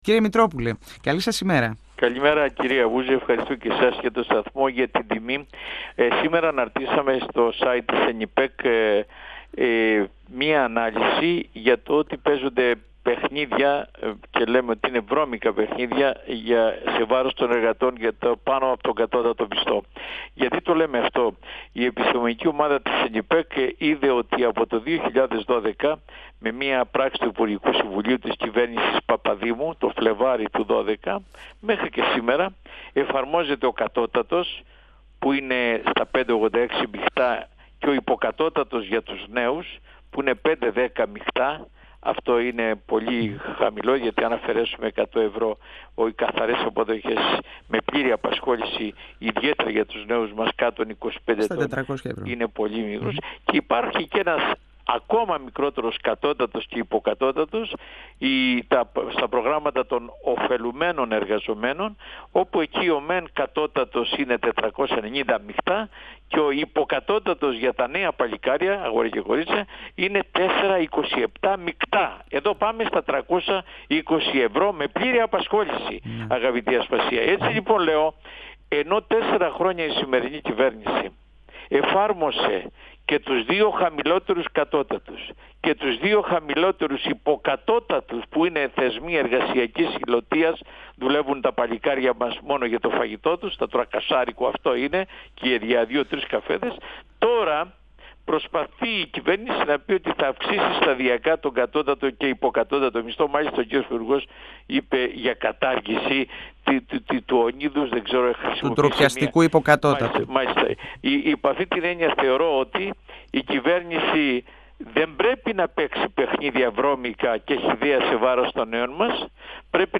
Ο εργατολόγος, πρόεδρος της ΕΝΥΠΕΚΚ Αλέξης Μητρόπουλος στον 102 fm της ΕΡΤ3